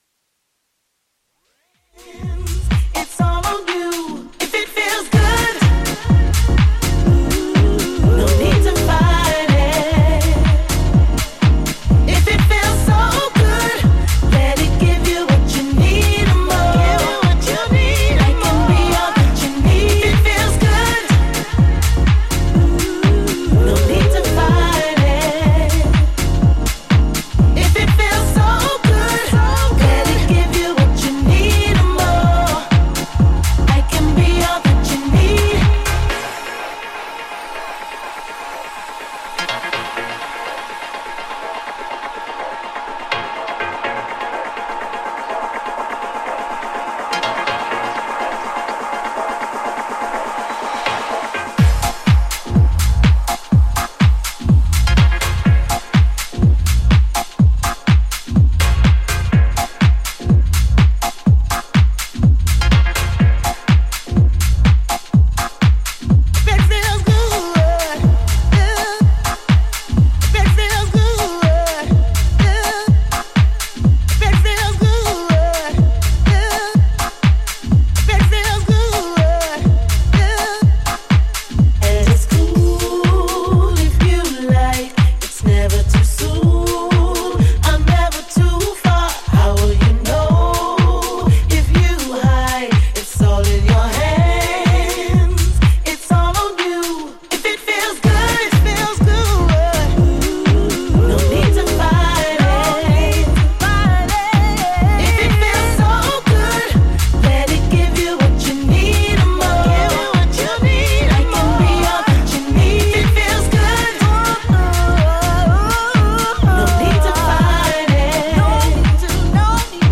ジャンル(スタイル) HOUSE / DEEP HOUSE